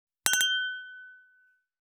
292ワイングラス乾杯,イタリアン,バル,フレンチ,夜景の見えるレストラン,チーン,カラン,キン,コーン,チリリン,カチン,チャリーン,クラン,カチャン,クリン,シャリン,チキン,コチン,カチコチ,
コップ